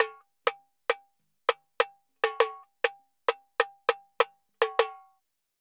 Tamborin Candombe 100_2.wav